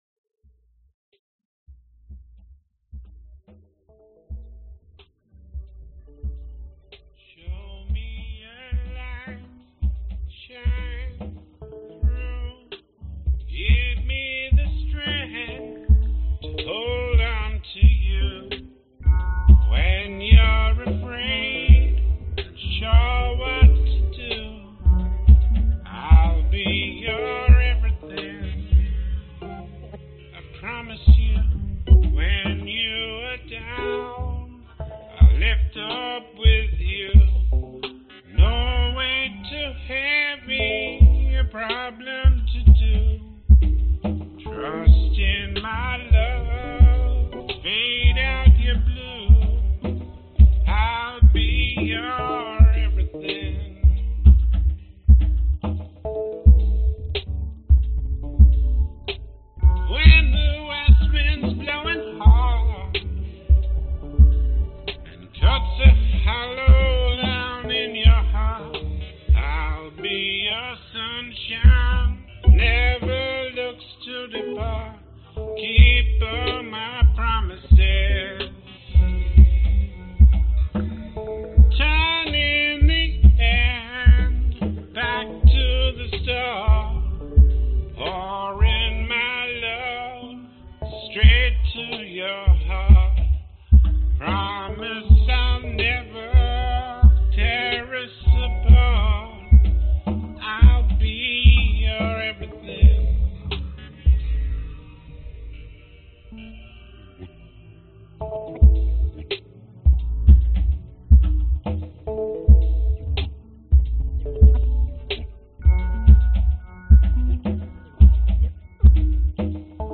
Tag: 蓝调 摇滚 雷鬼 慢板 男性主唱